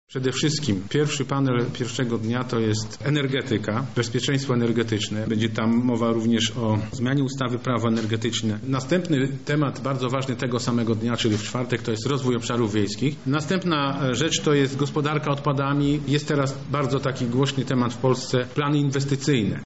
Co dokładnie zostanie poruszone na konwencie, tłumaczy marszałek Sławomir Sosnowski